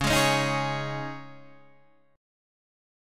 C# Suspended 2nd